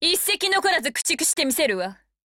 • 声优：田中理惠